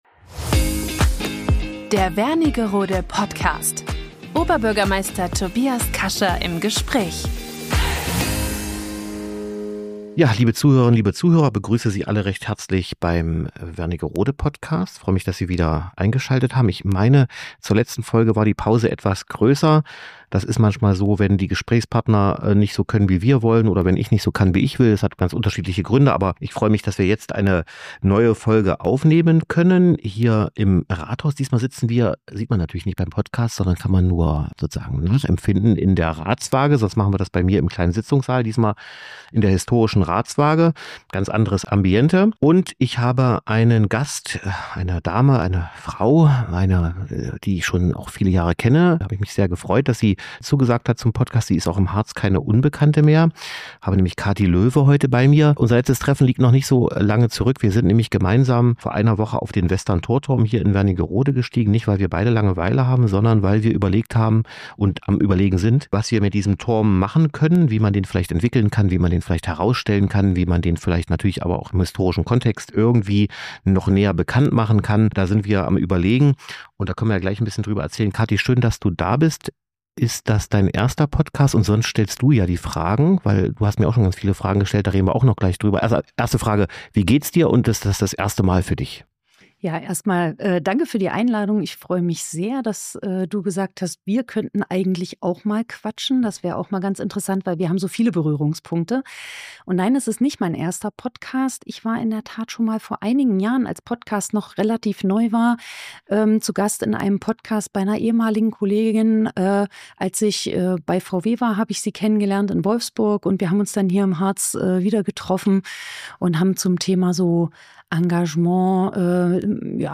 Ein Gespräch über Tatkraft, regionale Identität und das gute Gefühl, dort etwas zu bewegen, wo man zu Hause ist.